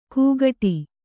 ♪ kūgaṭi